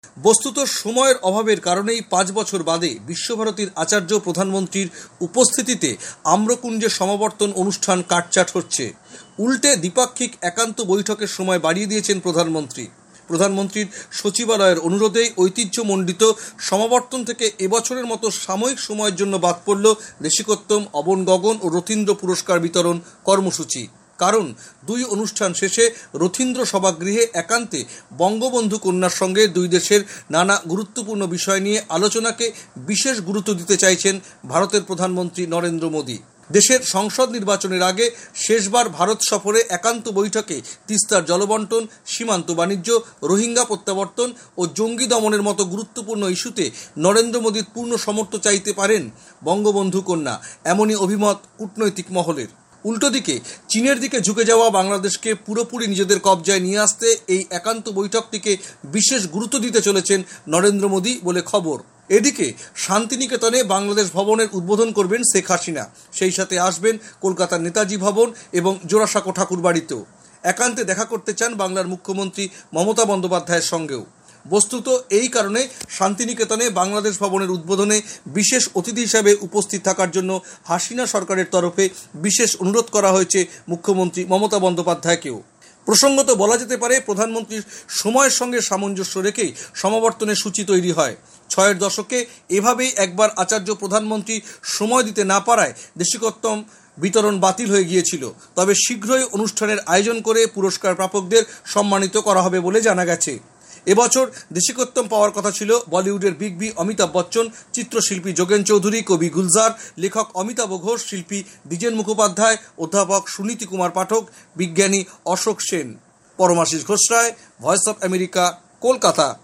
বিস্তারিত জানাচ্ছেন কলকাতা থেকে